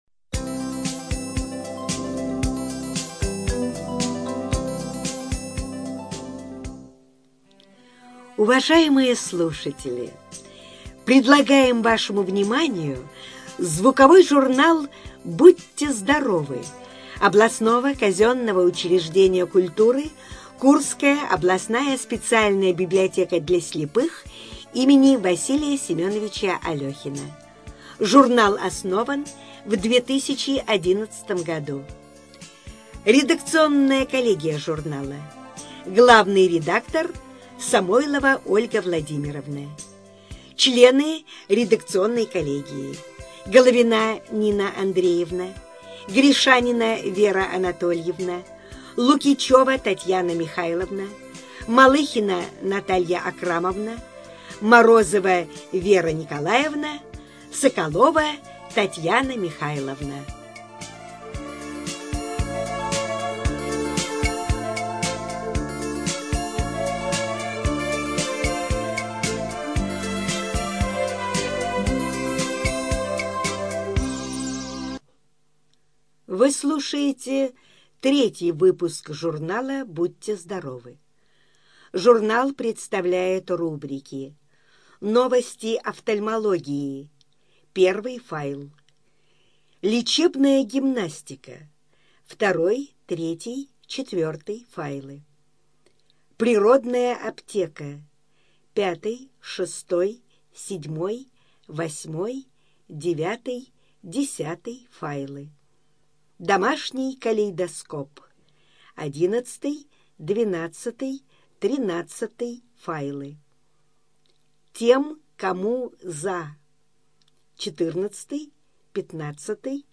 Студия звукозаписиКурская областная библиотека для слепых